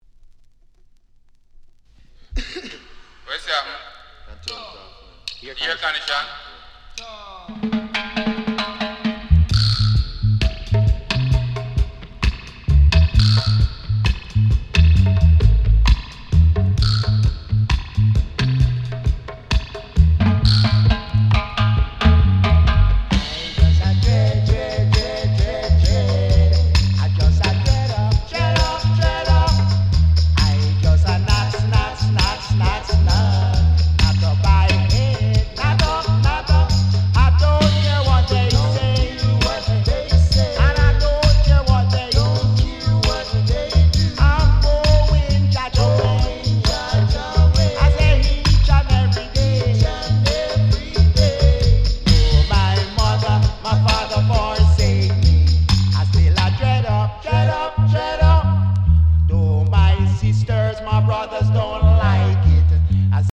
Stereo
Género: Reggae Estilo: Roots Reggae